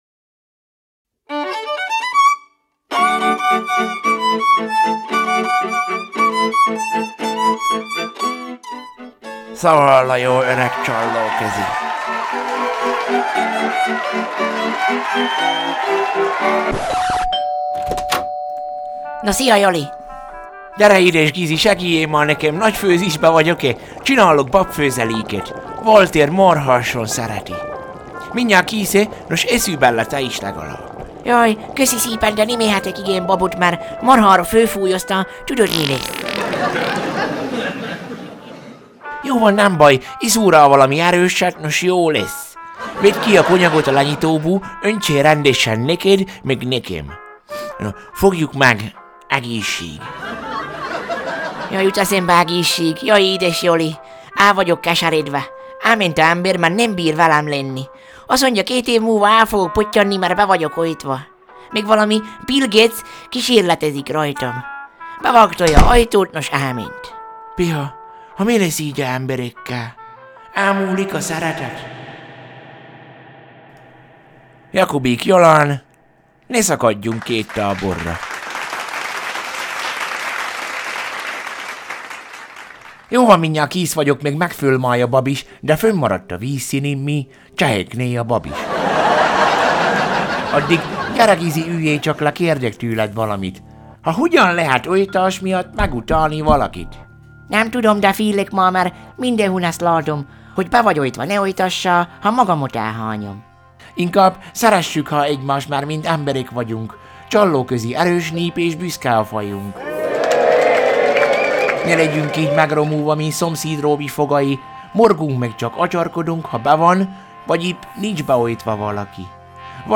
Szaval a jó öreg csallóközi
Zene: